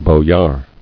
[bo·yar]